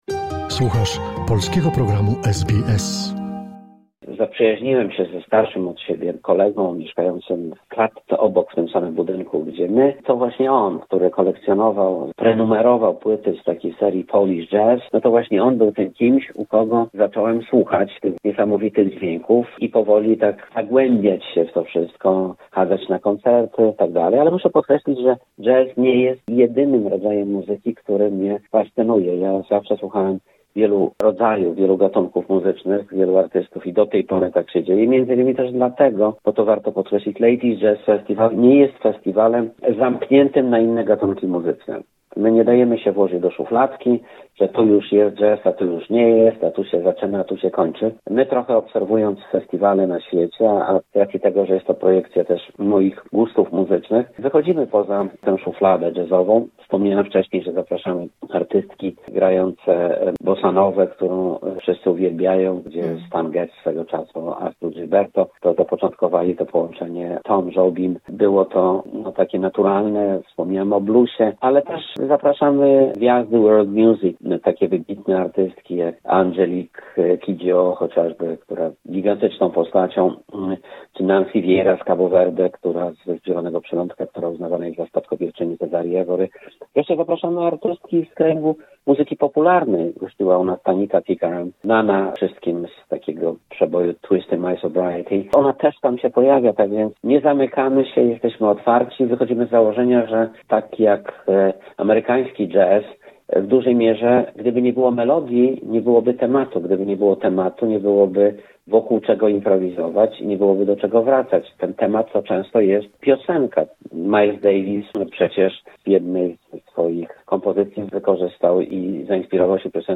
Druga część rozmowy